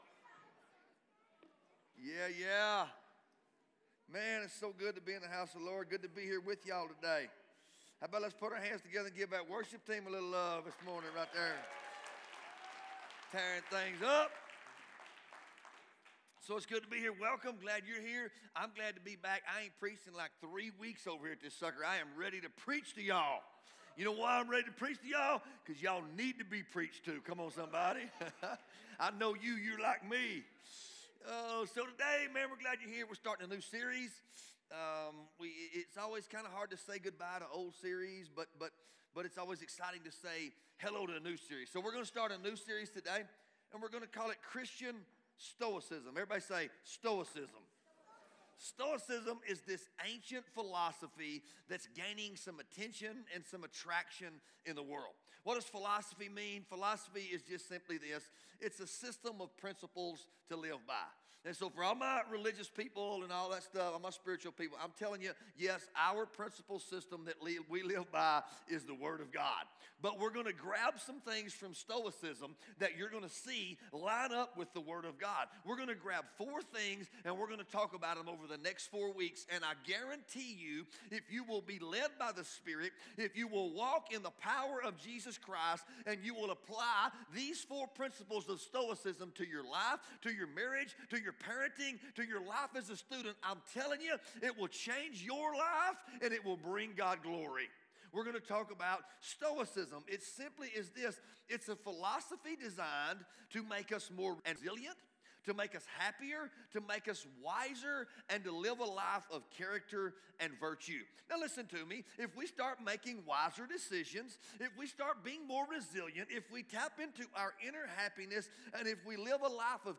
Sermons | Bethel Assembly of God Church